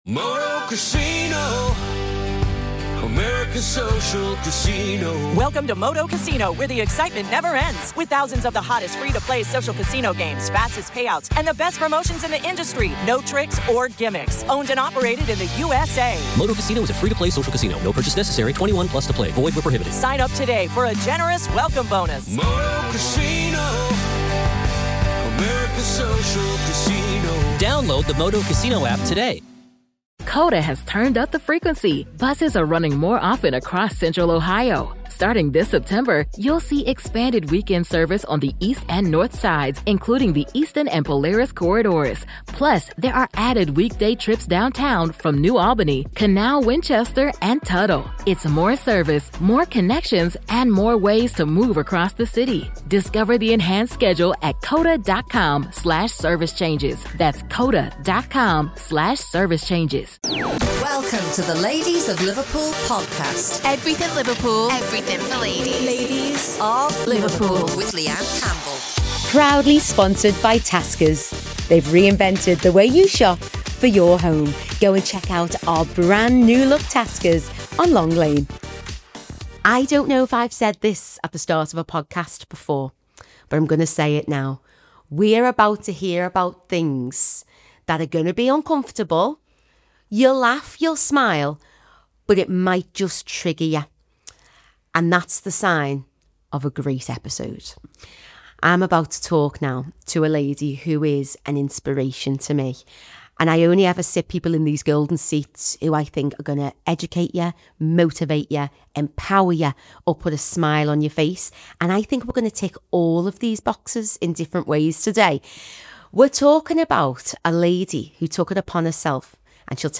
This conversation is raw, real, and surprisingly joyful.